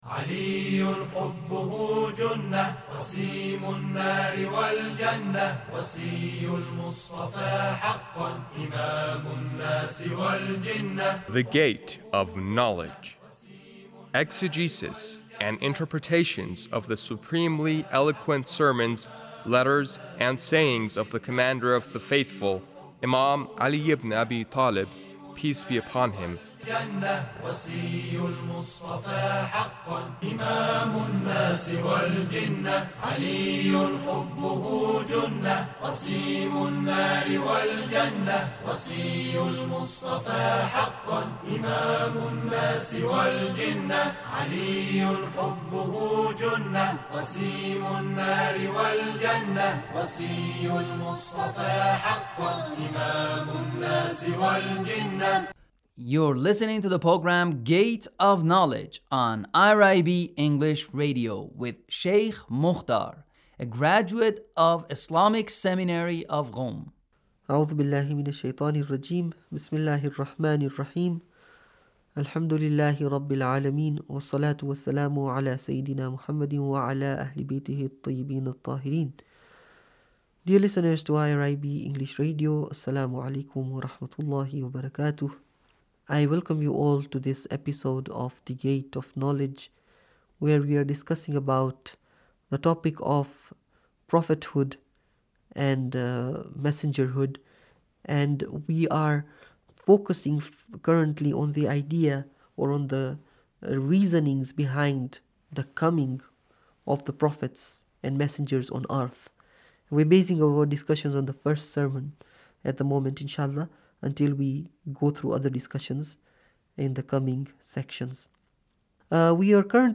Sermon 1 -